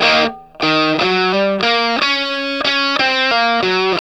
WALK1 60 EF.wav